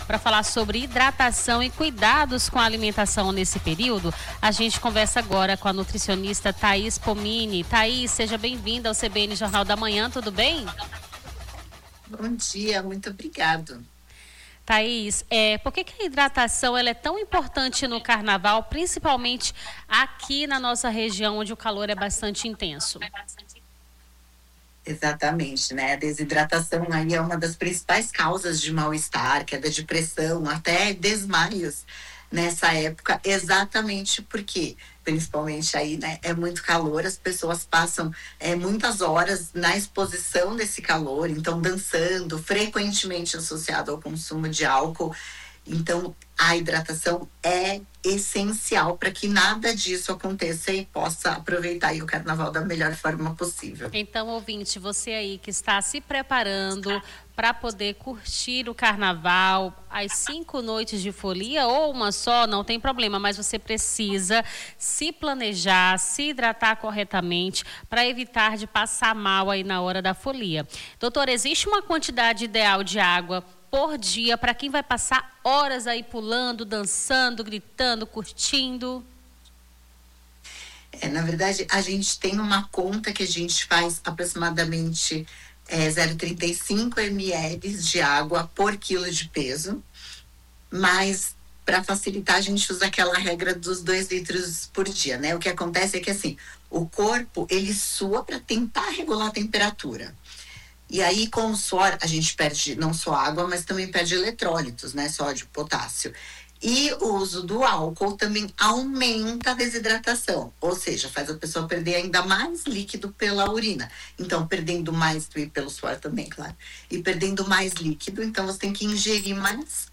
Nome do Artista - CENSURA - ENTREVISTA HIDRATACAO NO CARNAVAL - 12-02-26.mp3